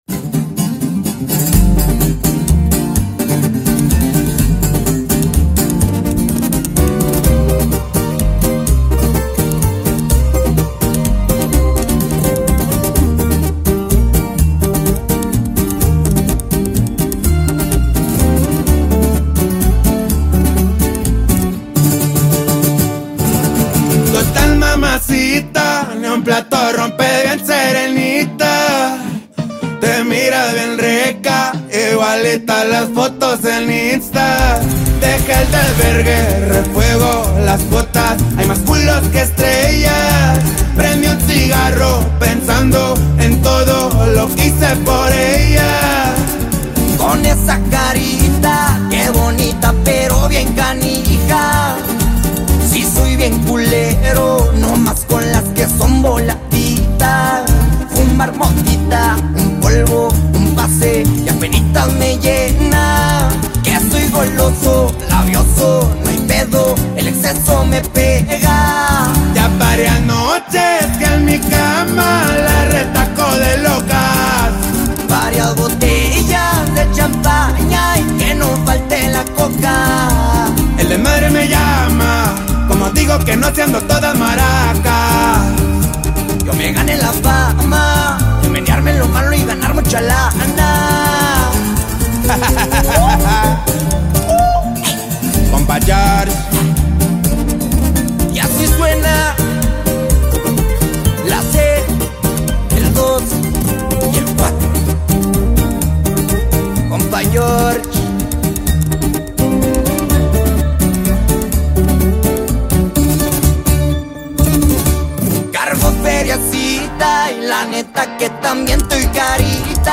vibrant new track